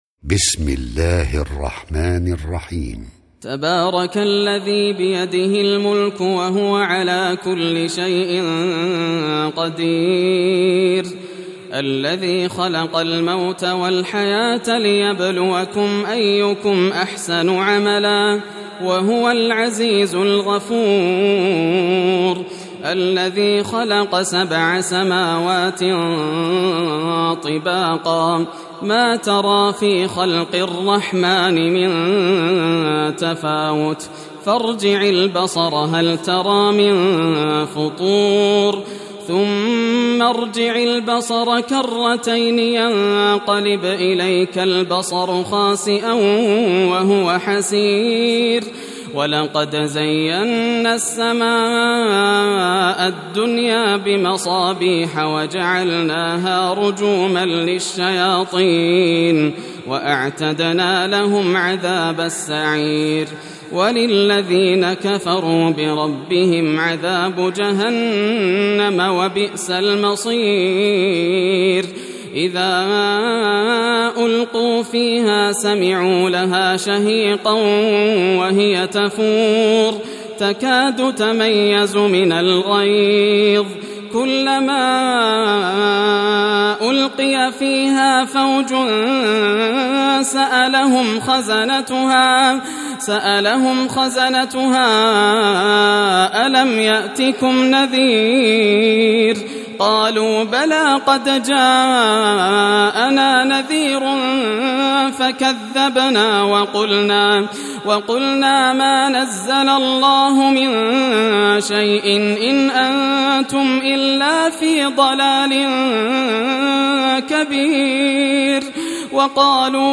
سورة الملك > المصحف المرتل للشيخ ياسر الدوسري > المصحف - تلاوات الحرمين